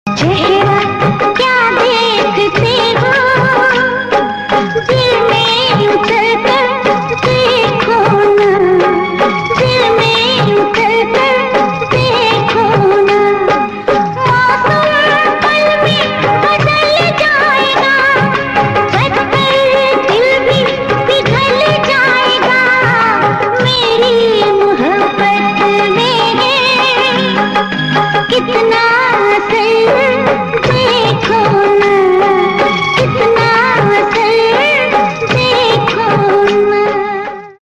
Bollywood 4K Romantic Song